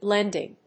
音節blénd・ing 発音記号・読み方
/ˈblɛndɪŋ(米国英語), ˈblendɪŋ(英国英語)/